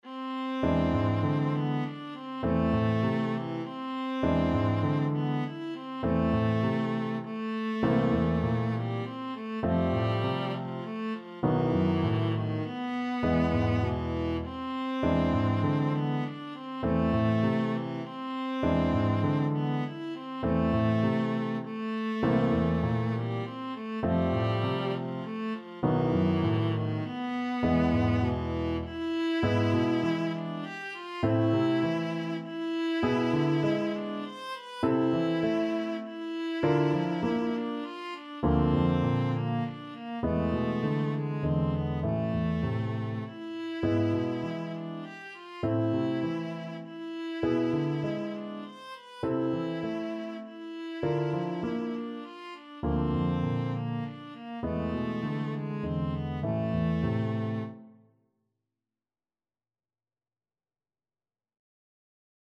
Viola version
Etwas bewegt
3/4 (View more 3/4 Music)
Classical (View more Classical Viola Music)